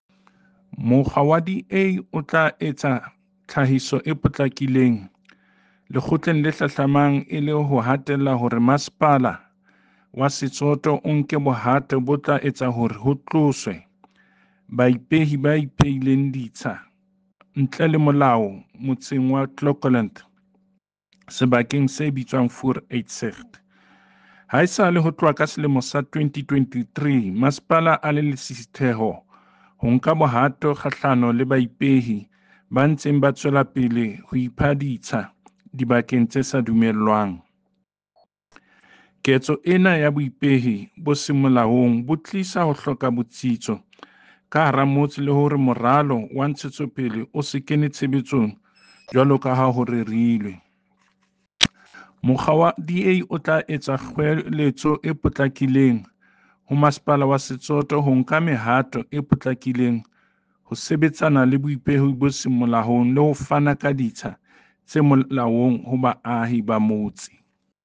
Sesotho soundbite by David Masoeu MPL